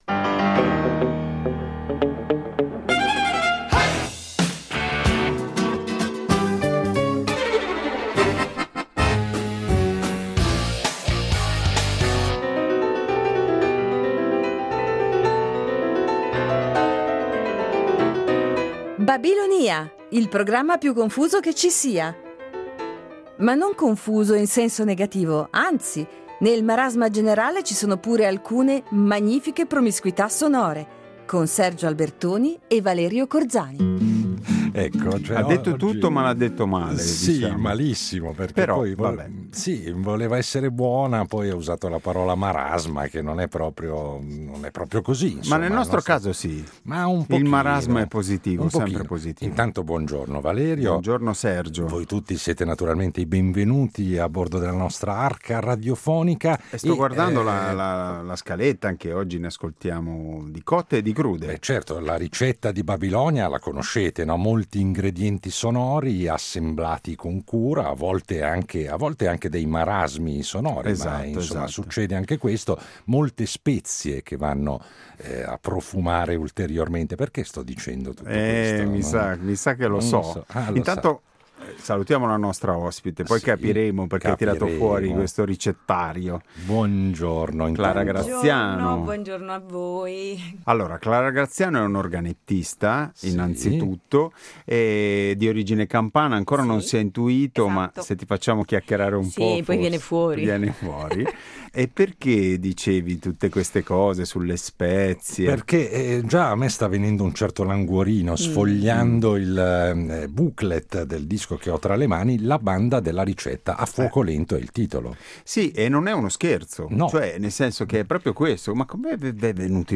che tuttora affianca nell’Orchestra Popolare Italiana dell’Auditorium Parco della Musica di Roma